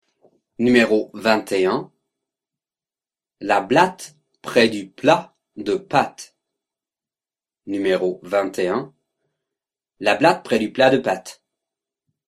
21 Virelangue